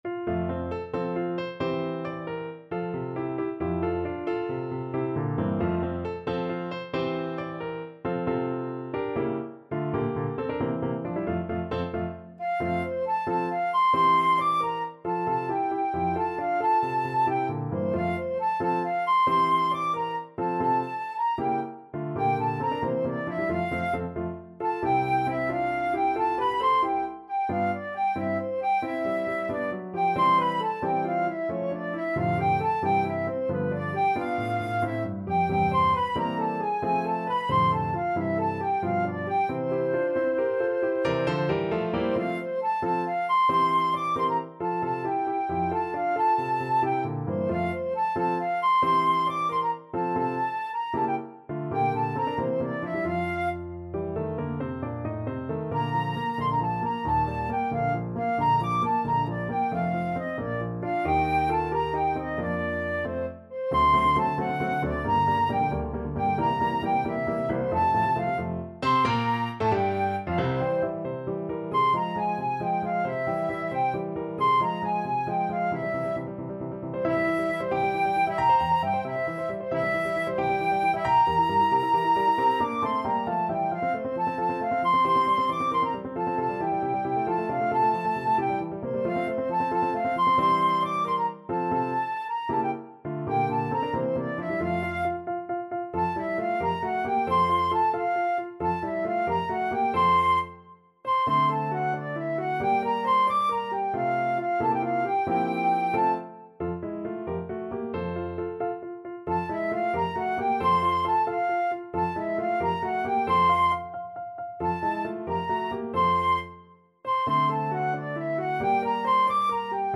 6/8 (View more 6/8 Music)
. = 90 Allegretto vivace
Classical (View more Classical Flute Music)